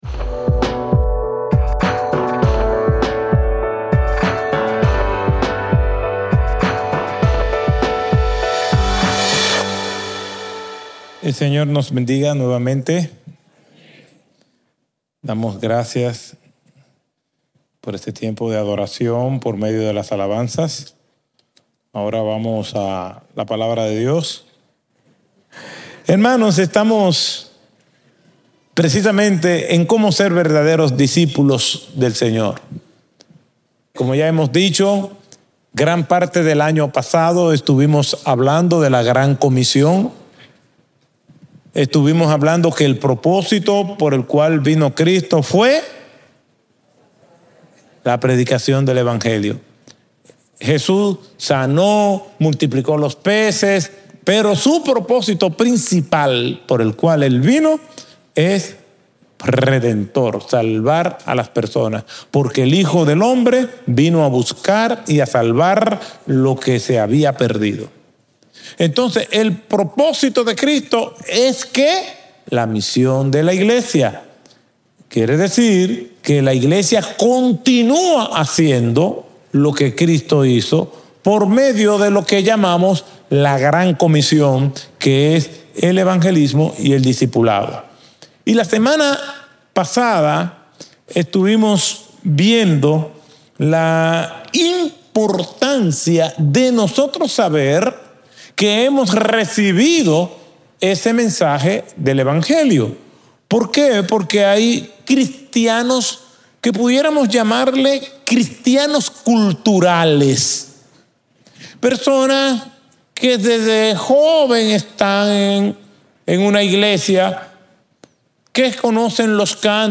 Un mensaje de la serie "Ser para hacer."